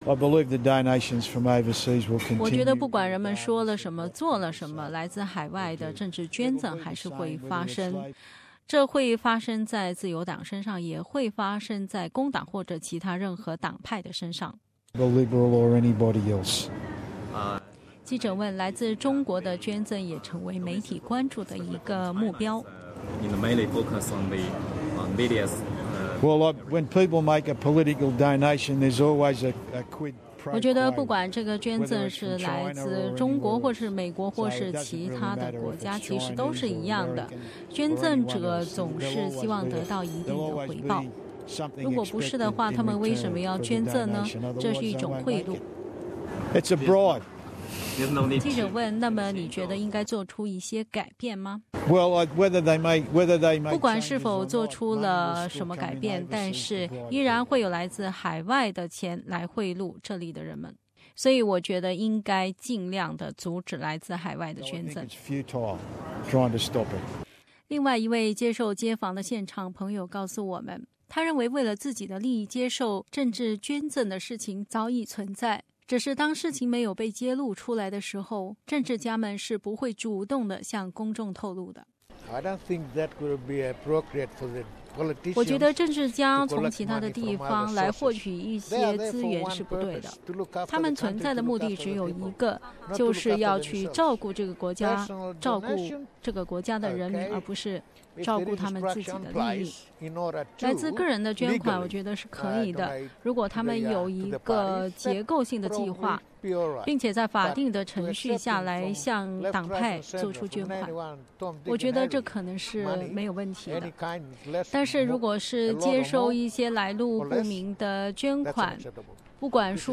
街访：你是否支持改革政治献金制度？
就这些问题，SBS电台记者在悉尼chatswood 街头采访了多位市民，大家各持己见，我们就一起来听听这些观点。